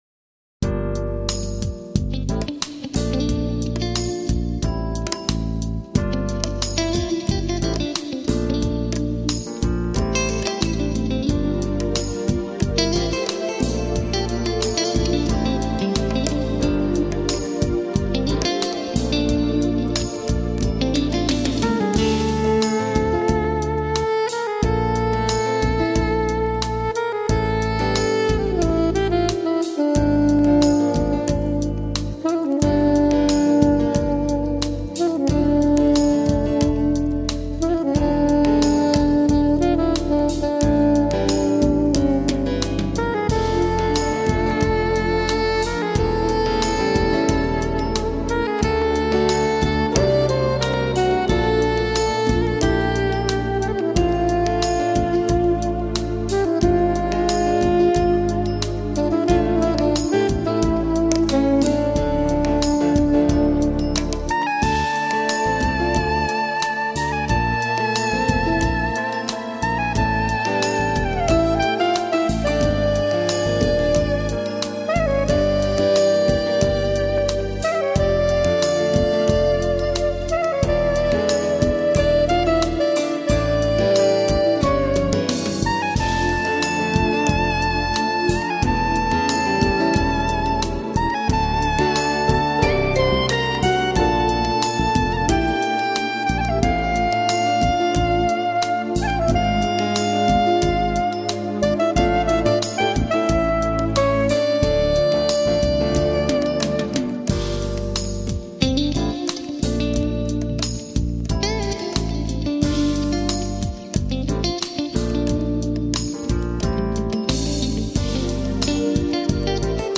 AAC Жанр: Chillout, Lounge, Trip-hop Продолжительность